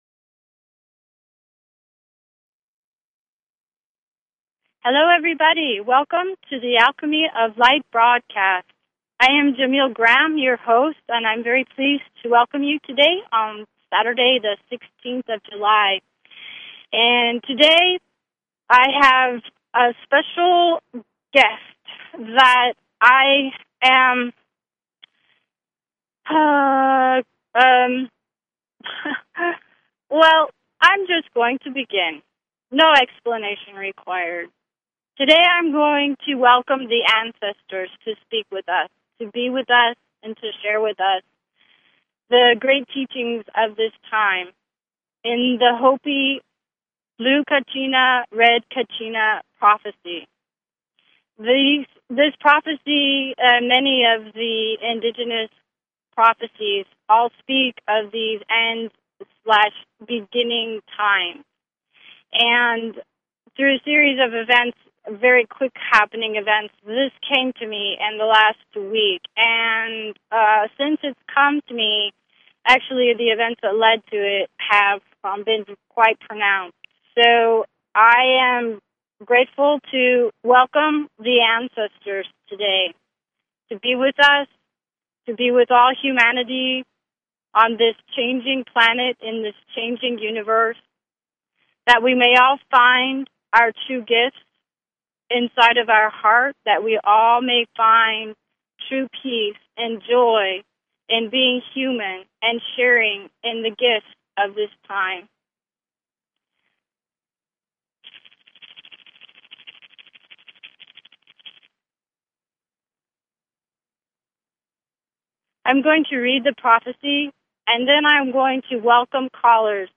Talk Show Episode, Audio Podcast, Alchemy_of_Light and Courtesy of BBS Radio on , show guests , about , categorized as